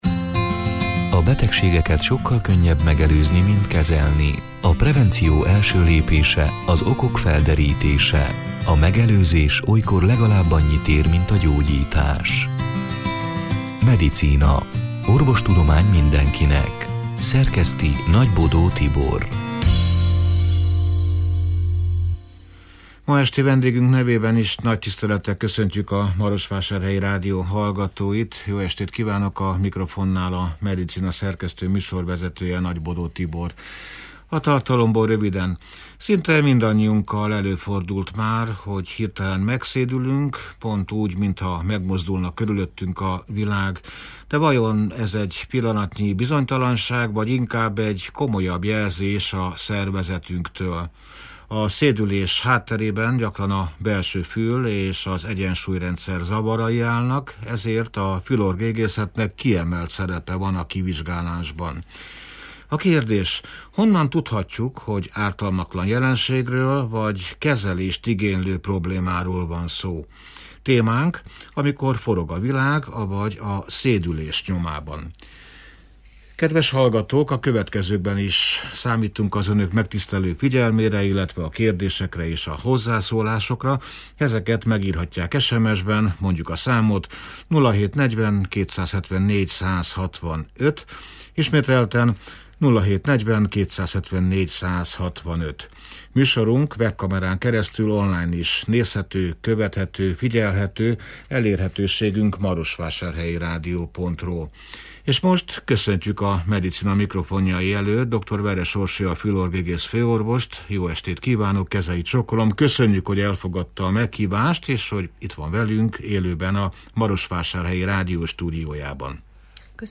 (elhangzott: 2026. március 18-án, szerdán este nyolc órától élőben)